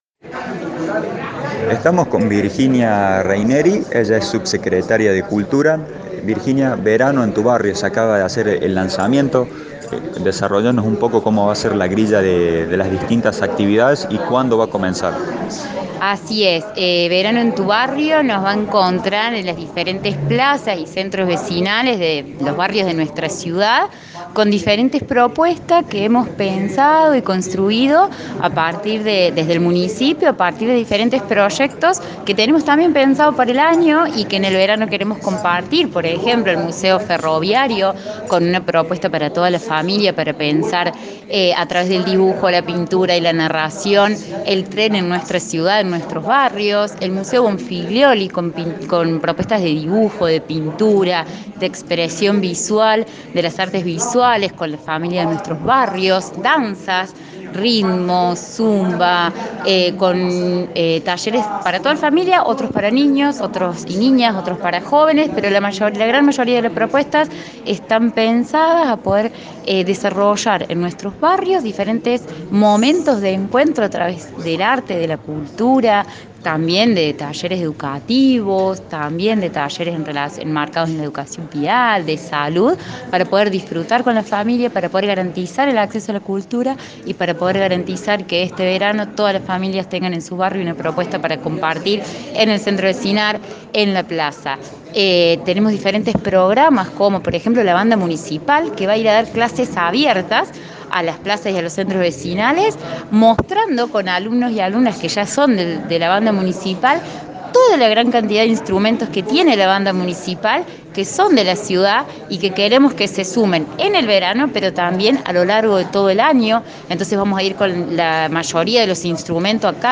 AUDIO – VIRGINIA REYNERI, SUBSECRETARIA DE CULTURA